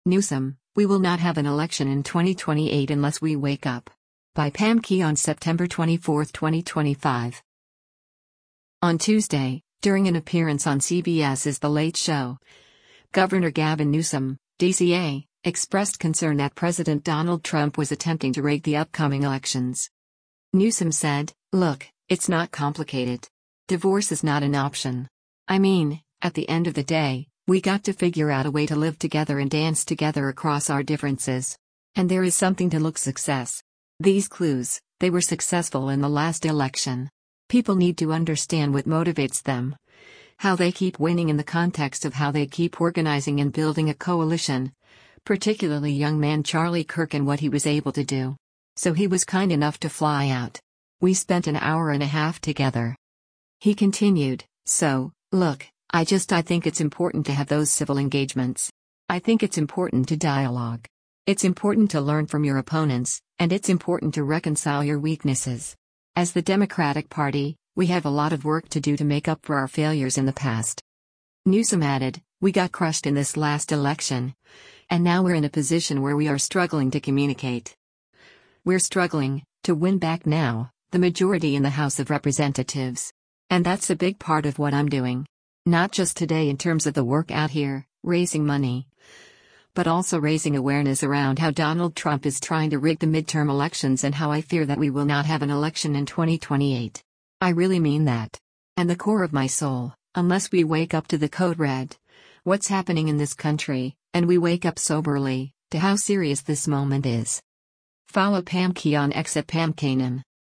On Tuesday, during an appearance on CBS’s “The Late Show,” Gov. Gavin Newsom (D-CA) expressed concern that President Donald Trump was attempting to “rig” the upcoming elections.